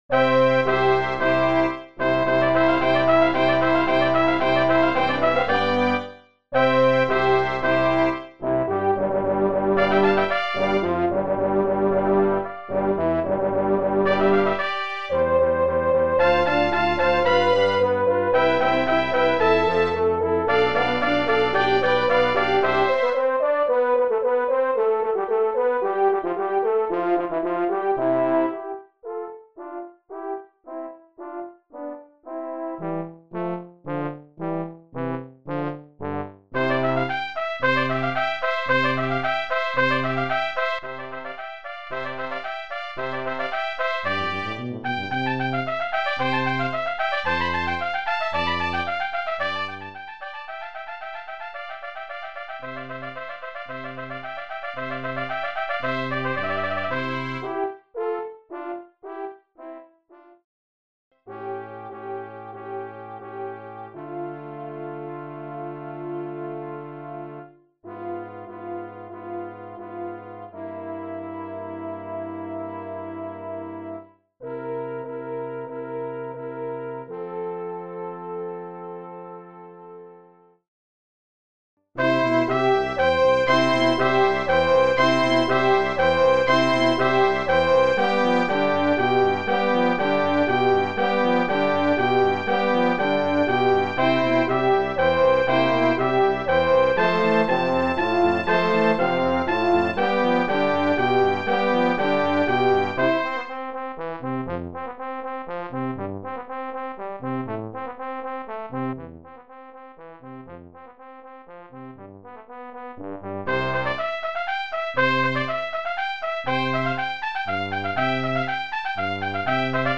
Brass Quintet
The trumpets are tacet on the 2nd movement.